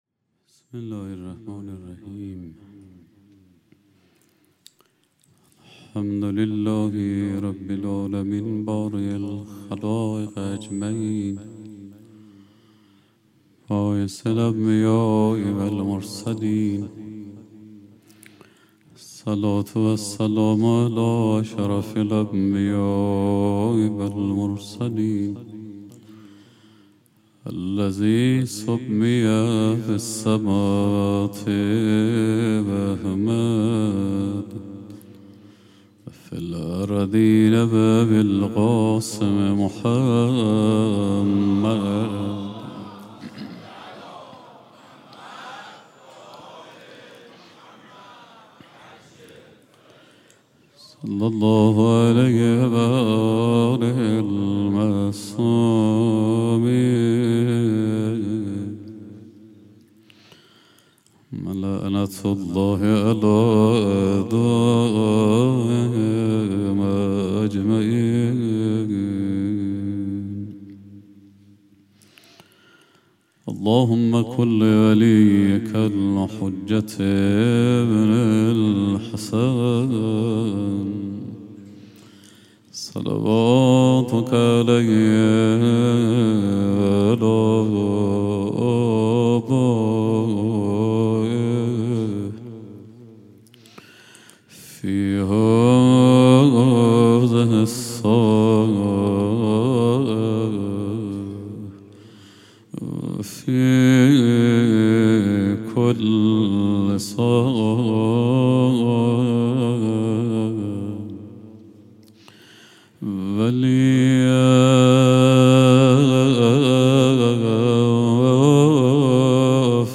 حسینیه انصارالحسین علیه السلام
روز چهارم محرم